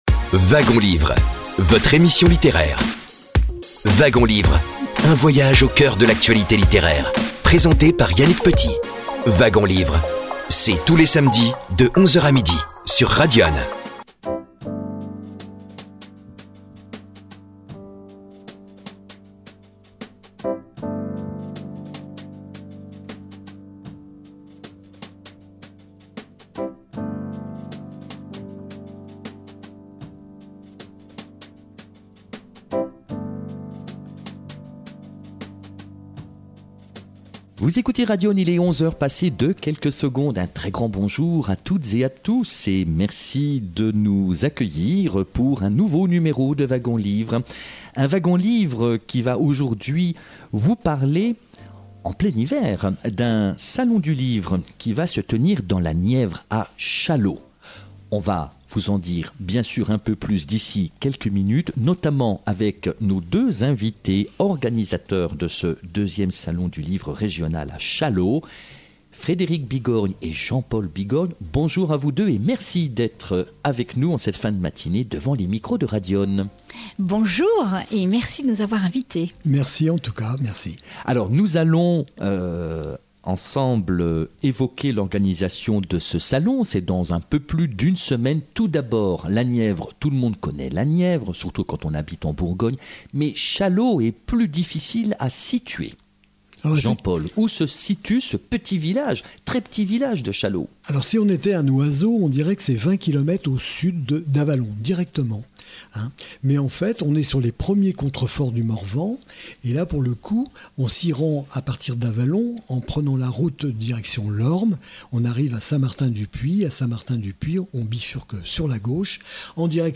Au cours de l’émission, trois des auteurs invités, interviennent :